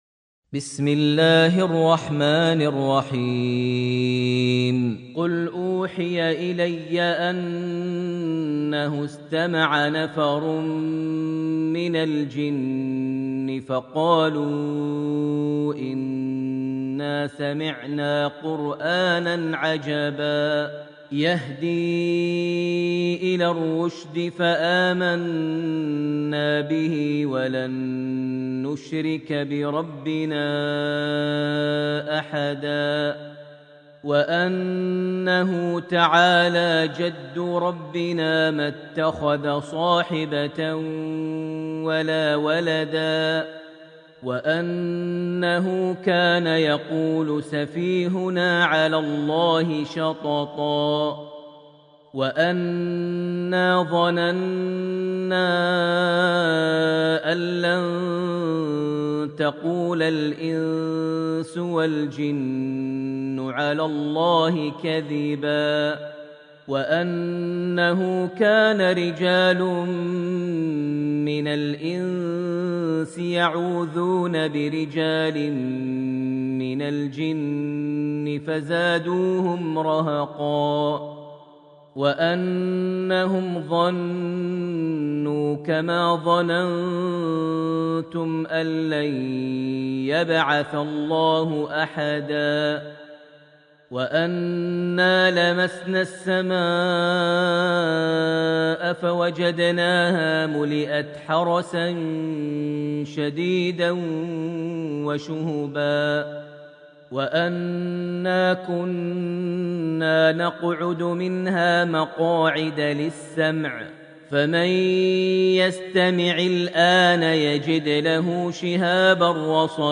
Surah Al-Jin > Almushaf > Mushaf - Maher Almuaiqly Recitations